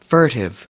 /fu_r´tiv/adj.یواشکی، دزدکی، پنهانی، مخفیانه